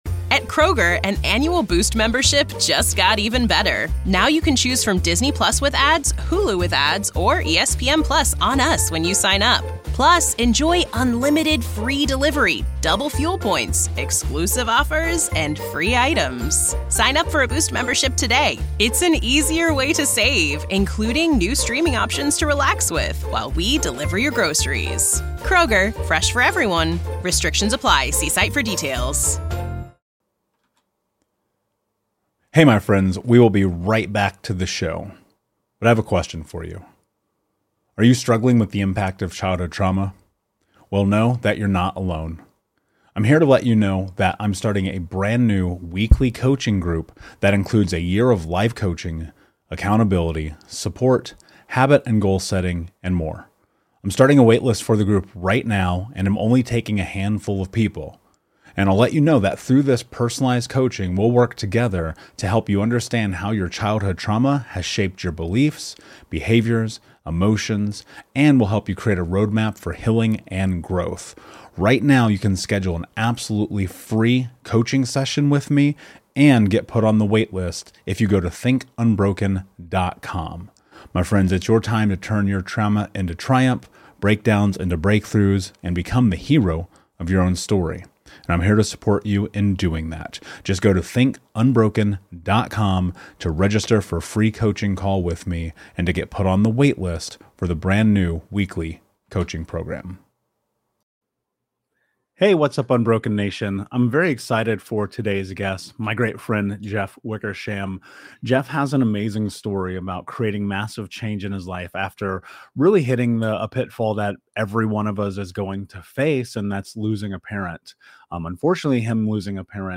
Unfortunately, he lost a parent to breast cancer, his mother. So this episode is a little bit emotional.